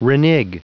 Prononciation du mot renege en anglais (fichier audio)
Prononciation du mot : renege